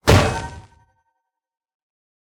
smash_air3.ogg